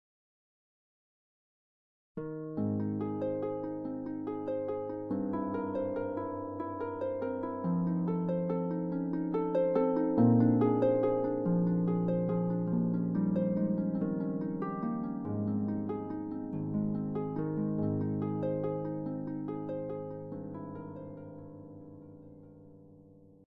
is for solo pedal harp